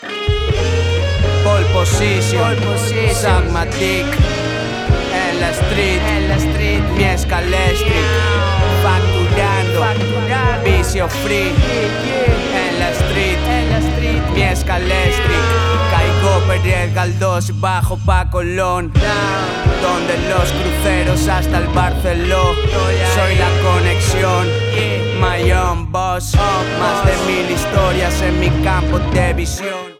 Hip Hop/Dj Tools